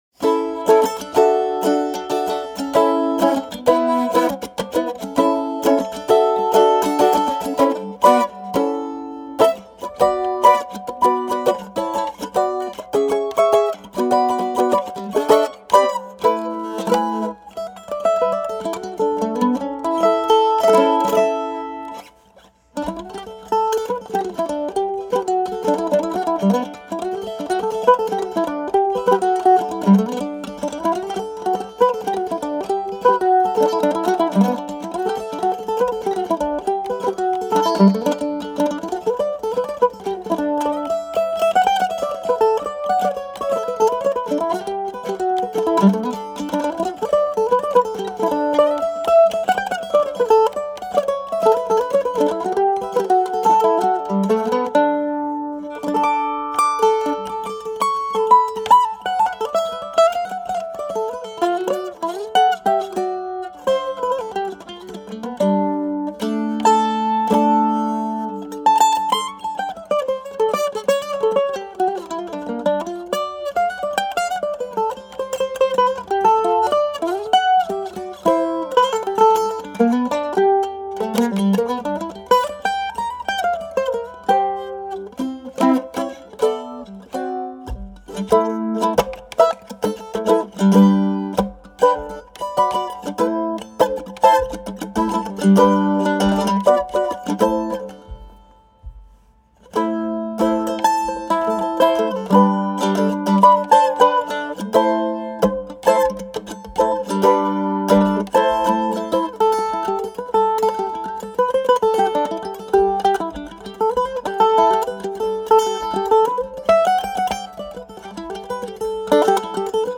c. 1929 Gibson A-0 Mandolin w/original hard case #88504
But in all other respects they were the same as Gibson's other mandolins...carved spruce top, maple back and sides, maple neck, ebony fret board and bridge.
The action is good, the mandolin plays well and the tone is excellent.